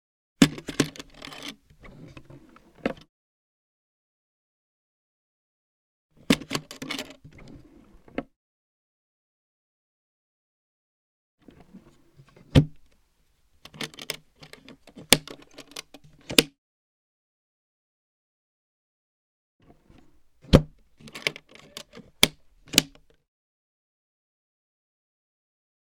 household
Flight Case Unlock and Open Lid 2